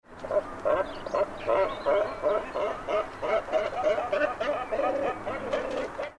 Kormoran – Natur erleben – beobachten – verstehen
Hier hören Sie eine Gruppe von Kormoranen.